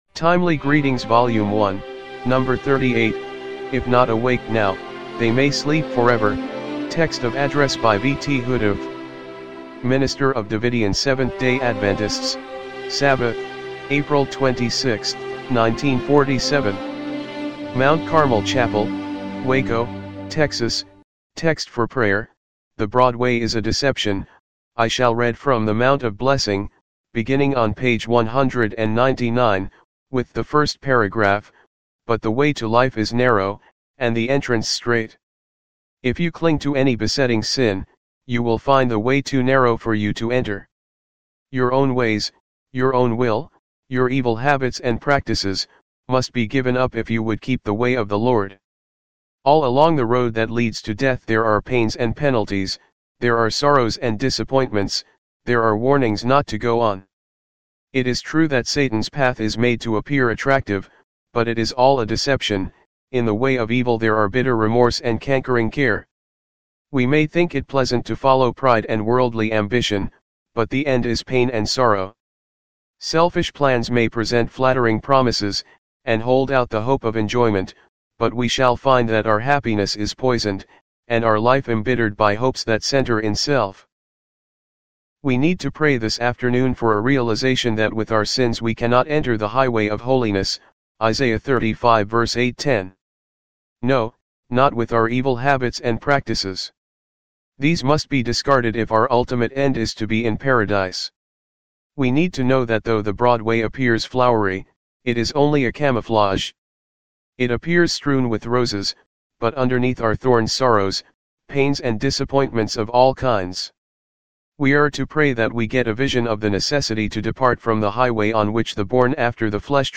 timely-greetings-volume-1-no.-38-mono-mp3.mp3